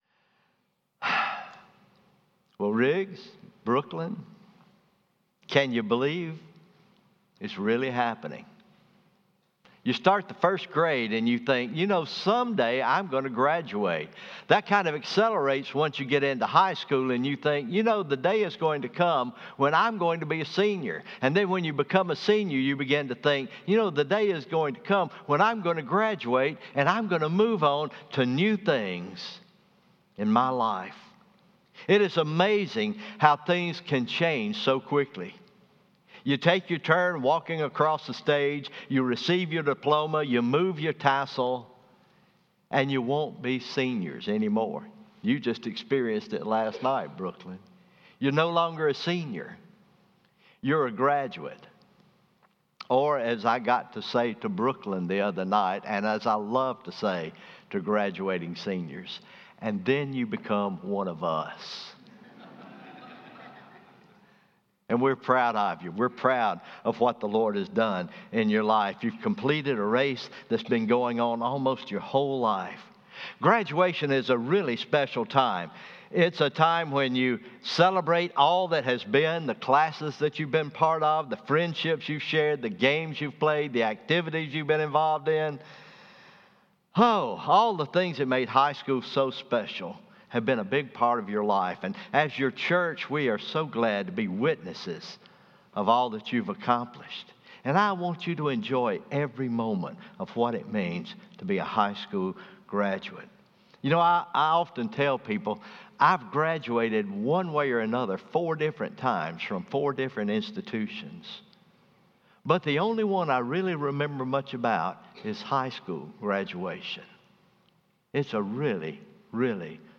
Morning Worship – Graduate Sunday